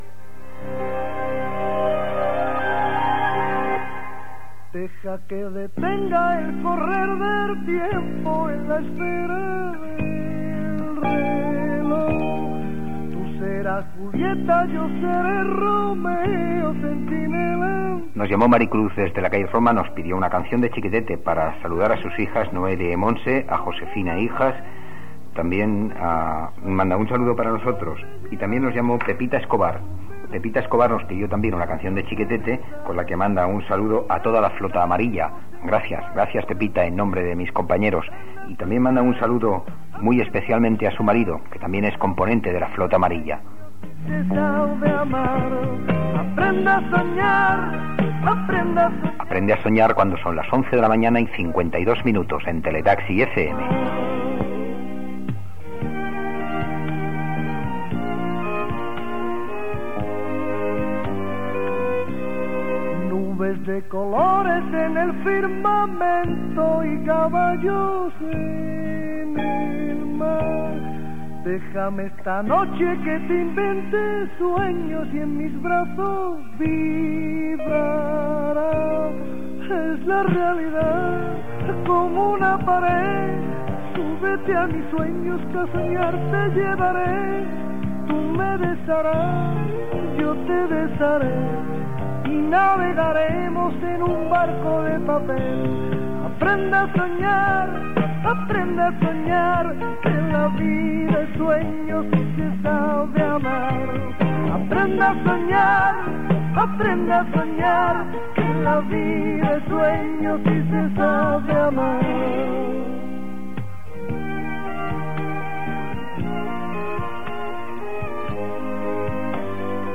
Salutacions de l'audiència, identificació, tema musical, telèfon del programa.
FM